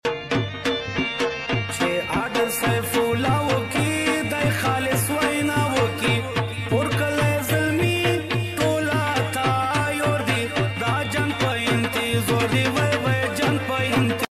Tiger 🐅 Sound Viral Video Sound Effects Free Download